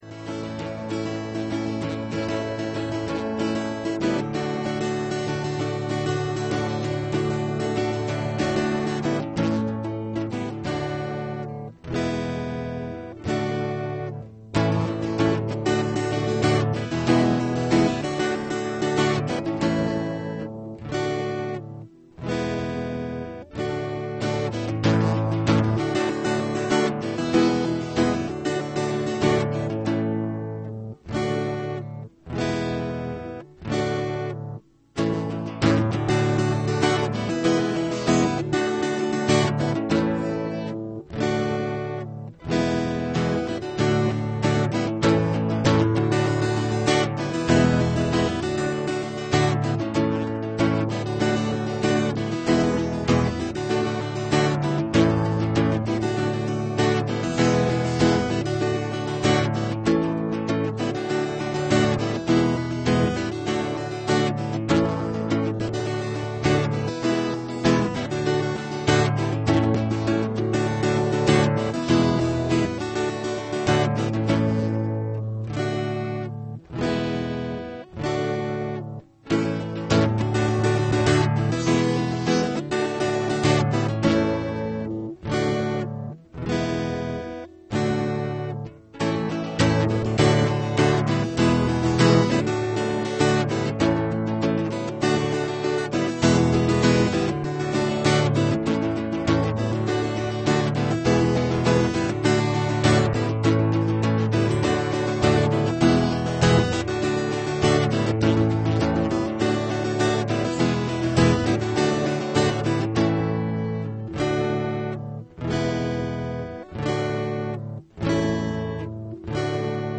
A message from the series "Christmas."